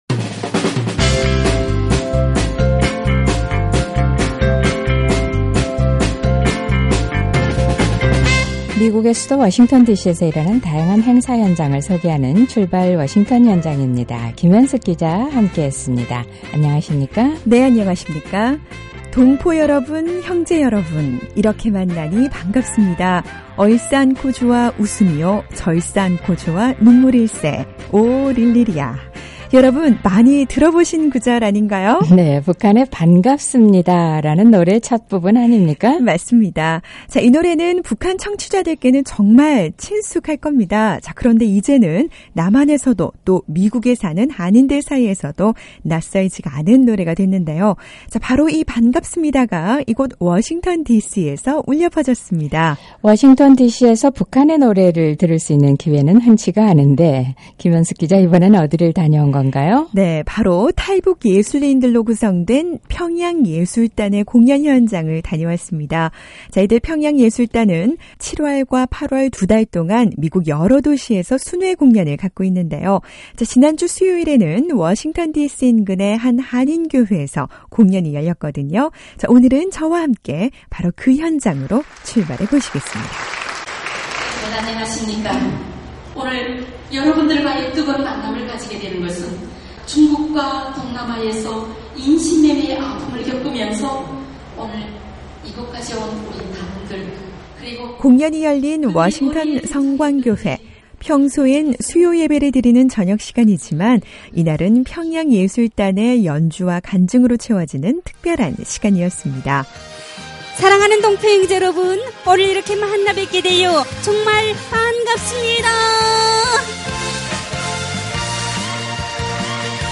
손풍금이라고 하는 아코디언과 북한 전통 악기 양금 연주. 바로 이런 북한의 음악을 접할 수 있는 공연이 워싱턴 디씨 인근의 한 한인교회에서 열렸습니다. 탈북 예술인들로 구성된 ‘평양 예술단’의 미주 순회 공연 현장이었는데요, 미모의 탈북 예술인들은 무대위에서 과연 어떤 이야기와 연주를 나눴을까요? 바로 그 현장을 찾아가봅니다.